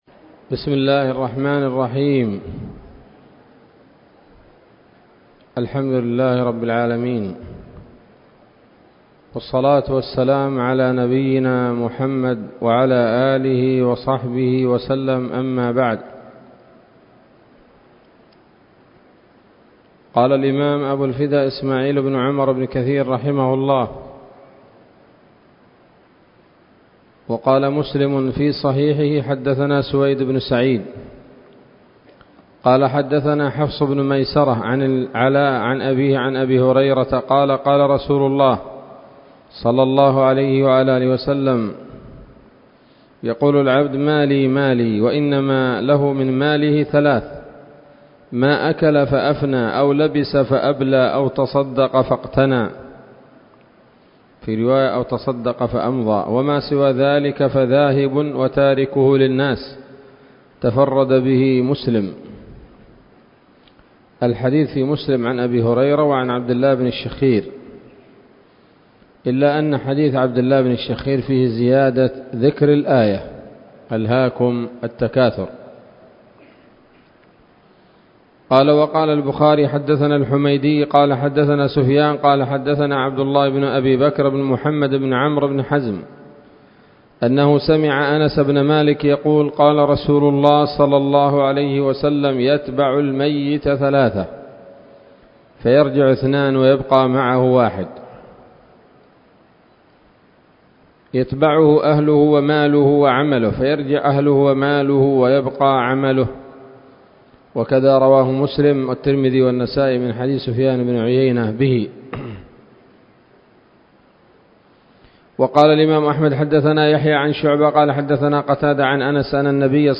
الدرس الثاني من سورة التكاثر من تفسير ابن كثير رحمه الله تعالى